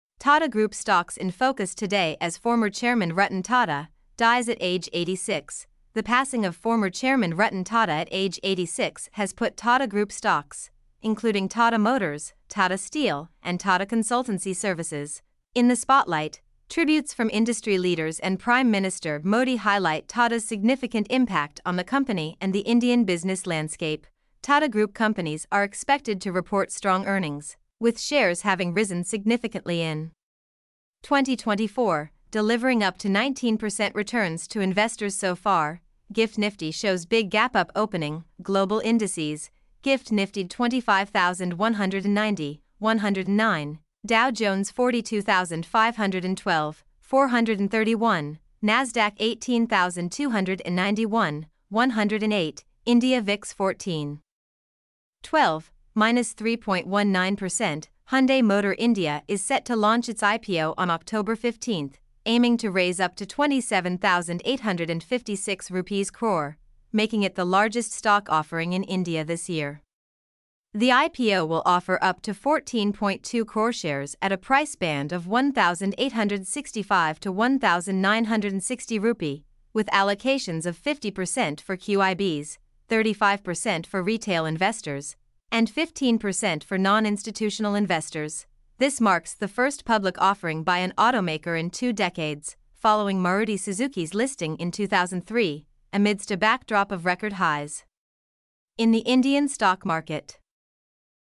mp3-output-ttsfreedotcom-1.mp3